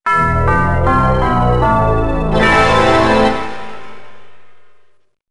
乐器类/重大事件短旋律－宏大/tune10.wav